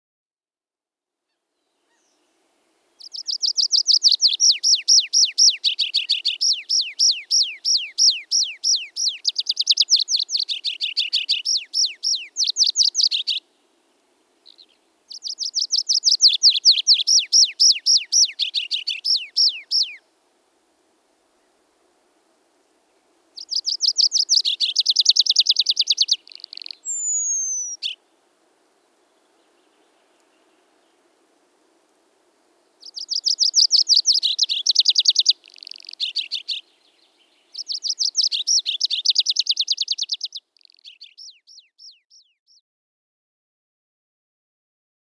Mikä lintu tässä laulaa?